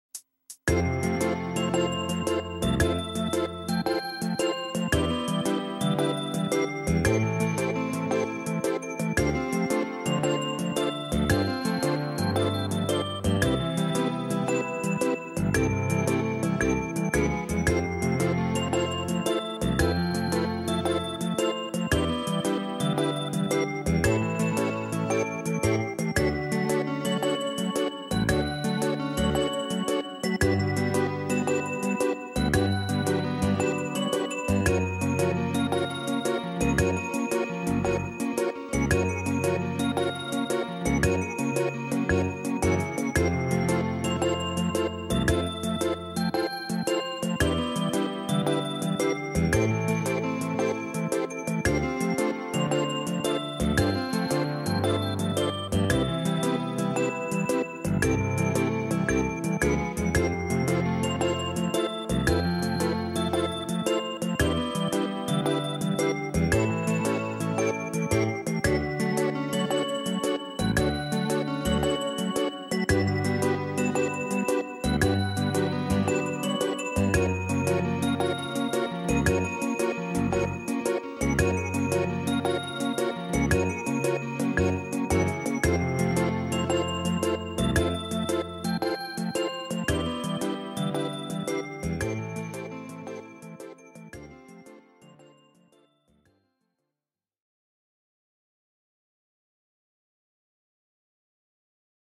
Très douce cette musique que ça endort 😴